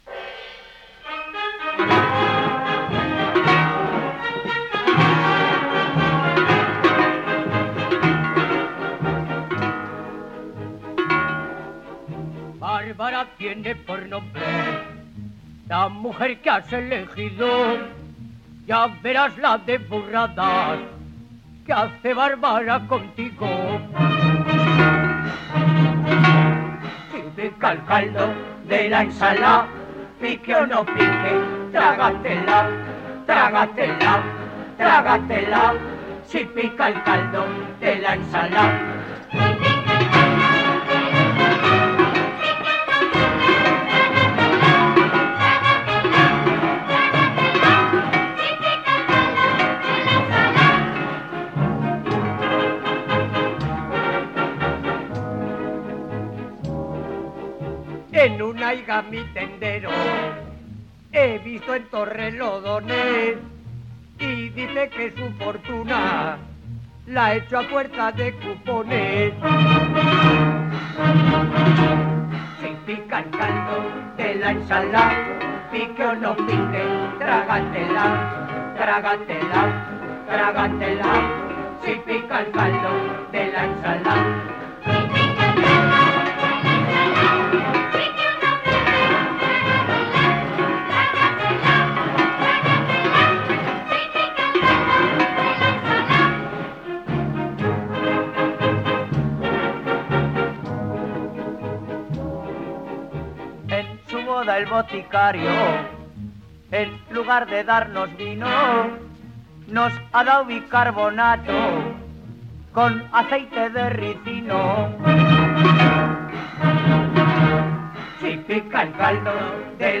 (cencerrada copuplets).
78 rpm.